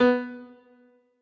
admin-amethyst-moon/b_piano2_v100l16o4b.ogg at 79f1561e7496e6b4e185f9f95c487e4ea47ec46d